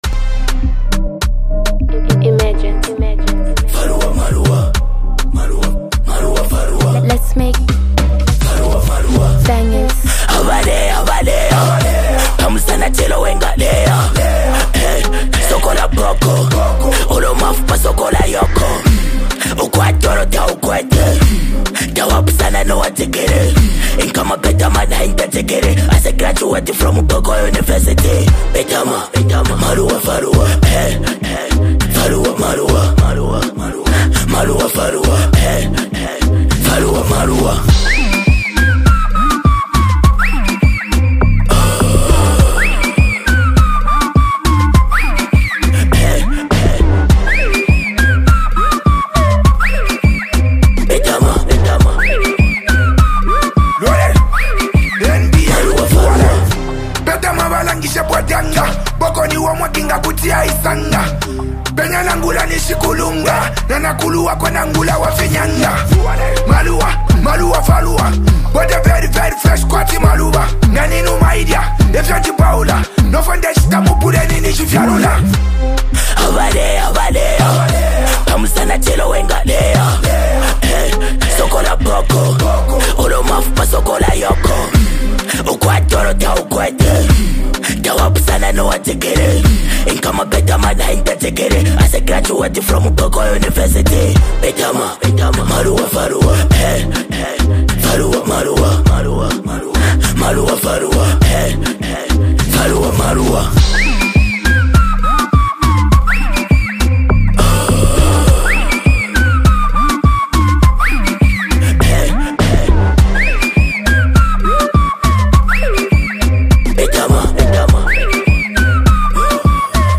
Zambian duo